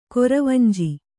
♪ koravanji